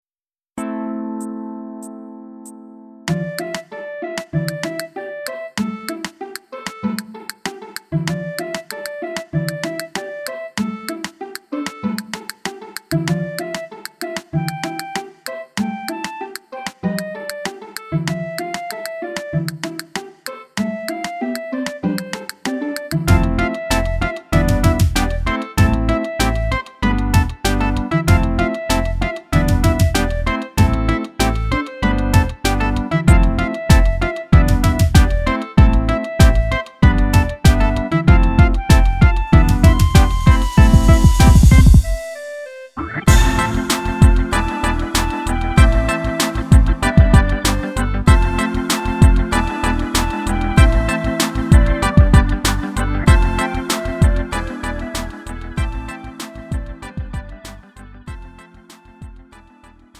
장르 가요